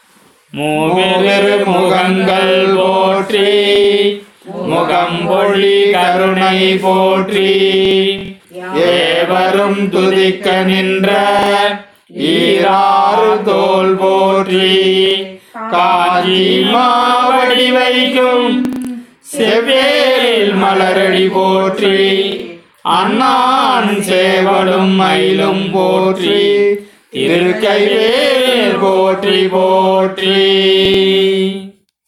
பாடியவர்கள்:    சங்கீர்த்தன இயக்கம் பக்த்தர்கள்